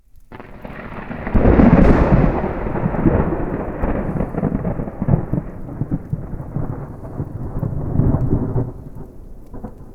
thunder-4.mp3